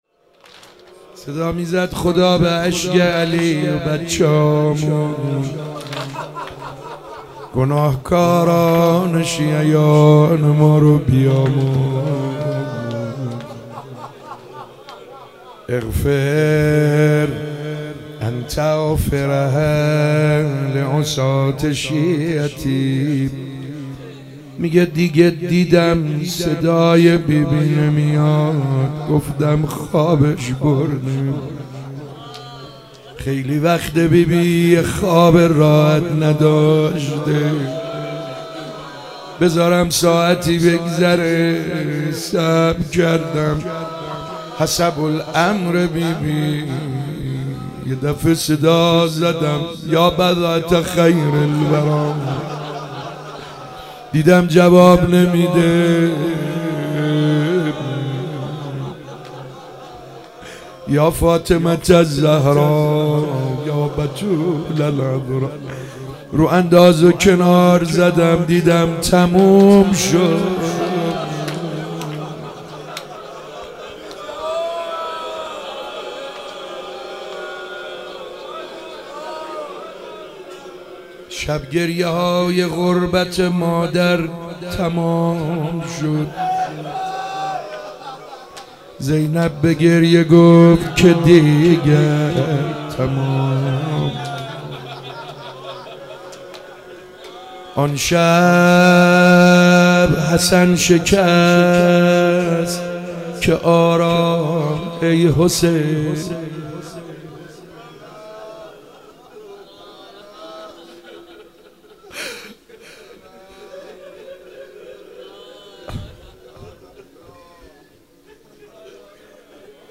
شب 4 فاطمیه 95 - روضه پایانی
شب 4 فاطمیه 95 روضه پایانی سعید حدادیان